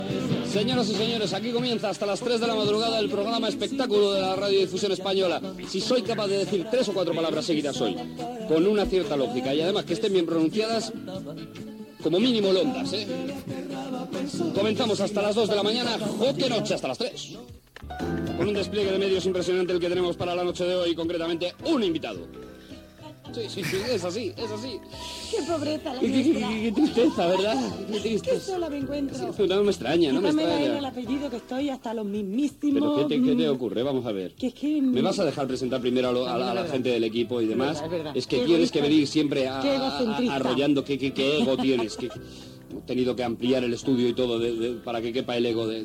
Inici del programa.
Entreteniment